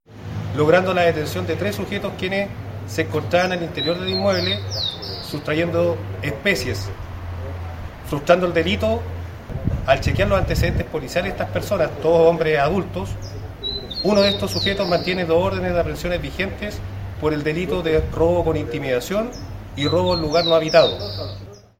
El capitán de Carabineros